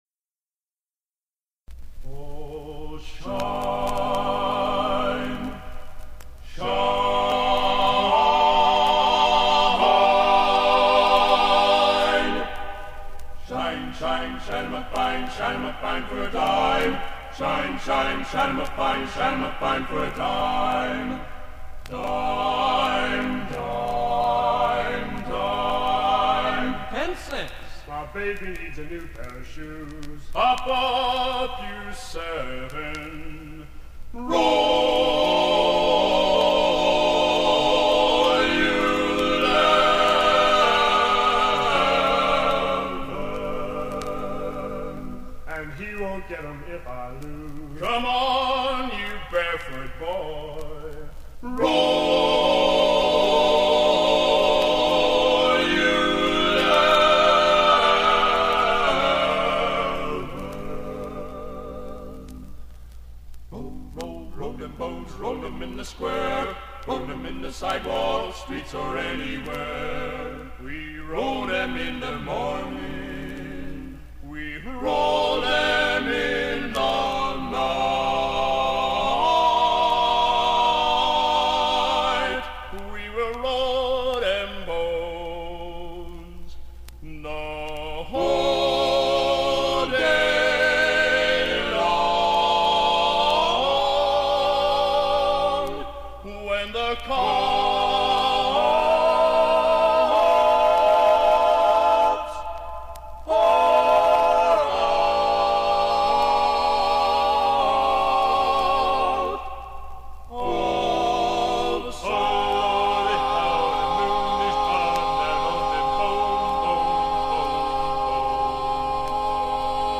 Location: West Lafayette, Indiana
Genre: | Type: Studio Recording